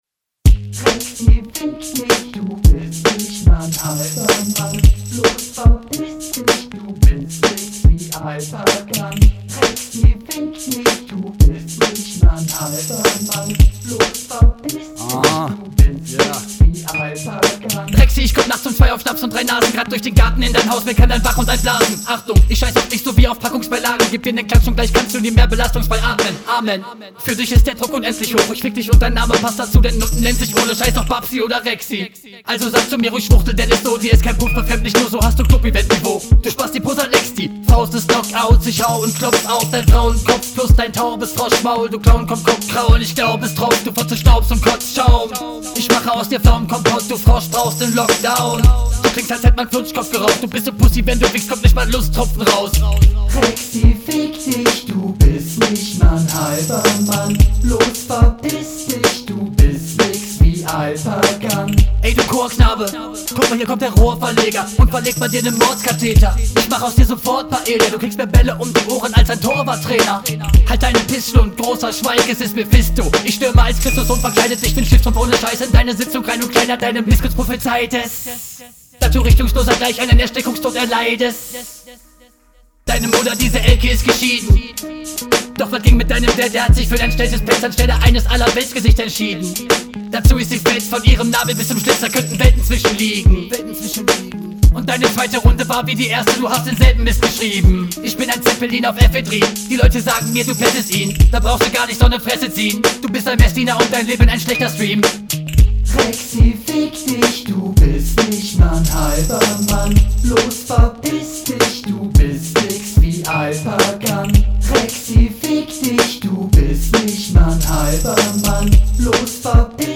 Ooh geiler Beat. Der schnelle Flow klingt auch echt nice.